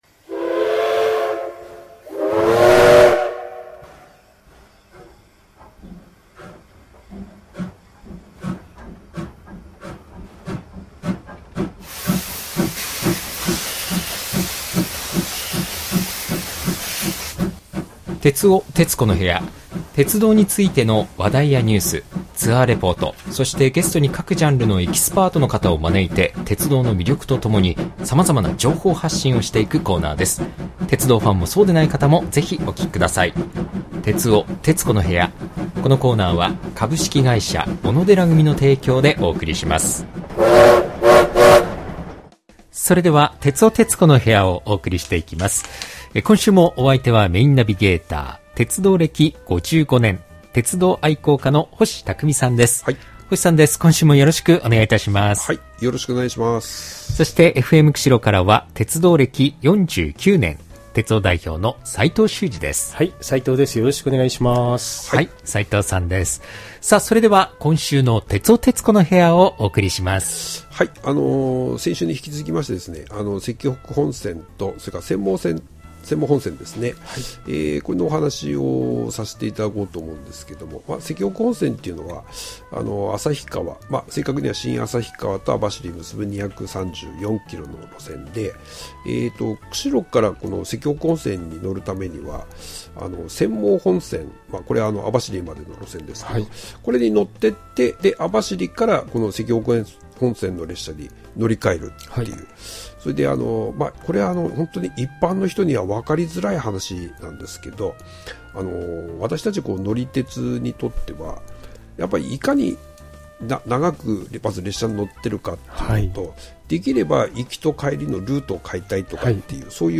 音鉄あります！